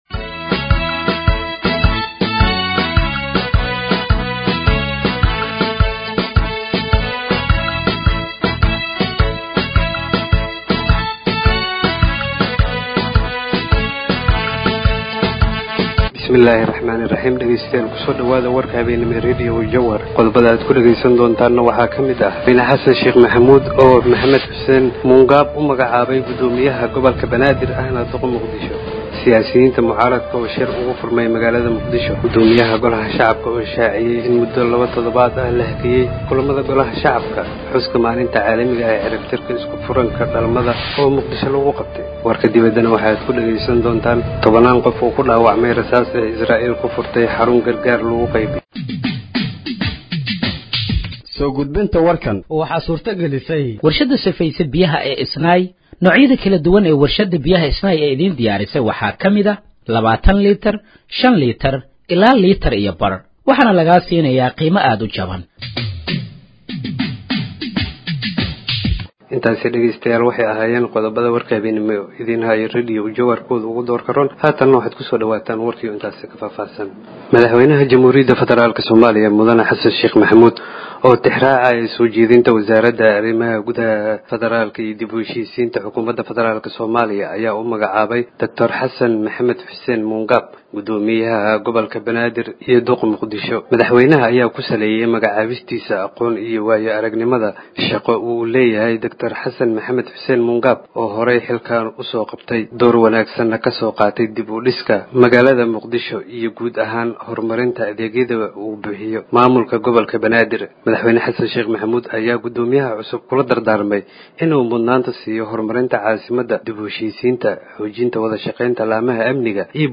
Dhageeyso Warka Habeenimo ee Radiojowhar 28/05/2025
Halkaan Hoose ka Dhageeyso Warka Habeenimo ee Radiojowhar